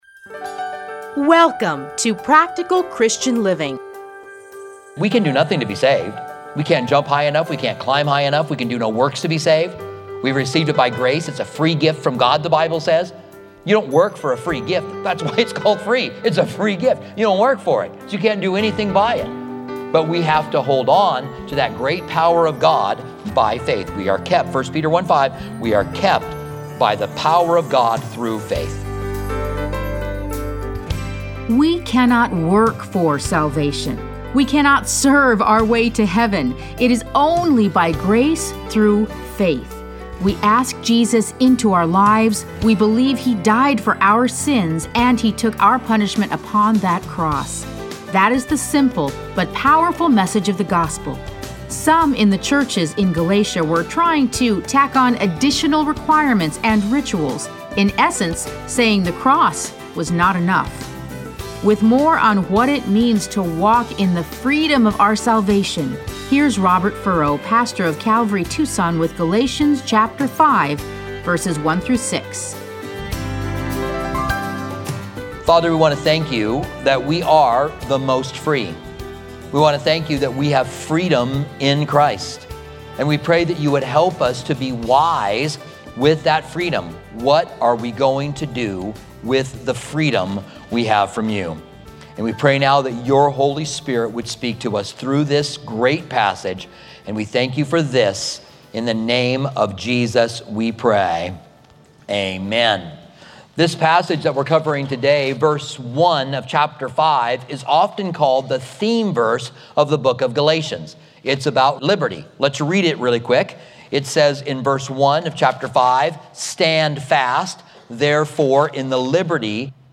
Listen to a teaching from Galatians 5:1-6.